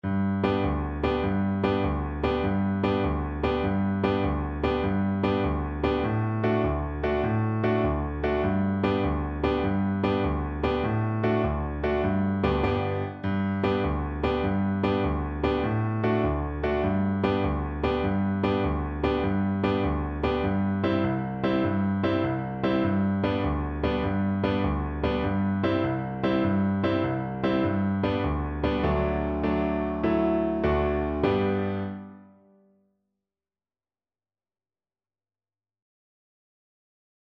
6/8 (View more 6/8 Music)
Allegro .=c.100 (View more music marked Allegro)